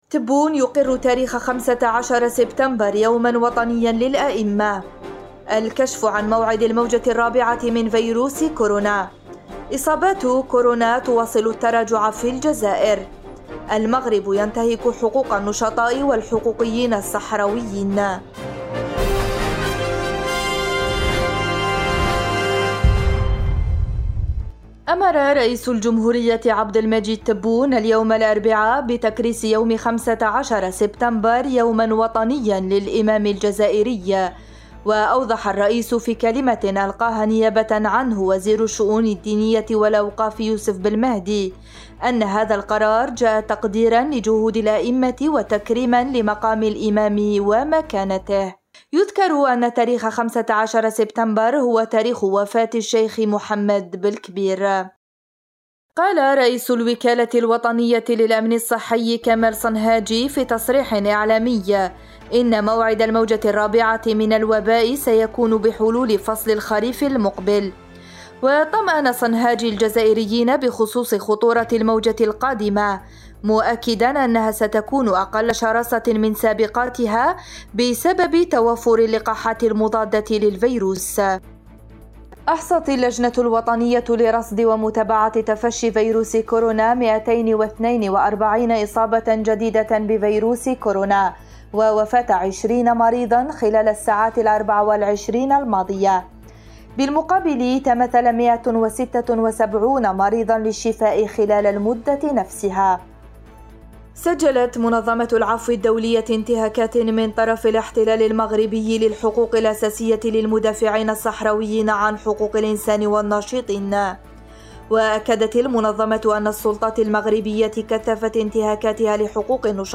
النشرة اليومية: تبون يقر تاريخ 15 سبتمبر يوما وطنيا للأئمة – أوراس